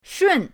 shun4.mp3